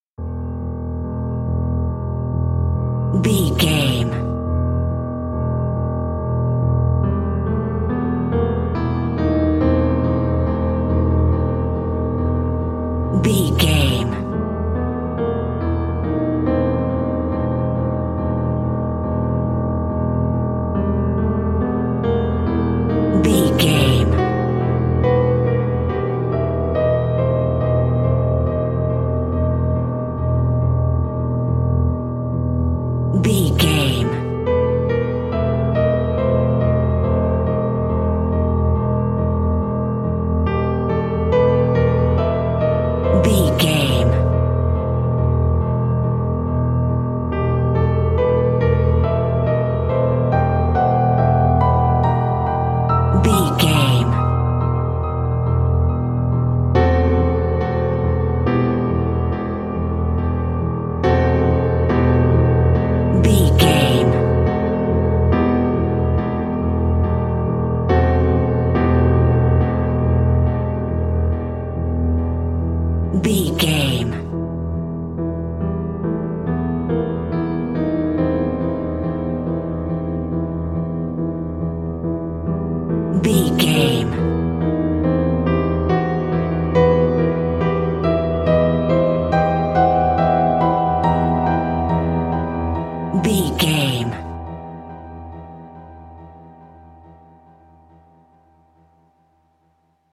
Aeolian/Minor
synthesiser
tension
ominous
dark
suspense
haunting
creepy
spooky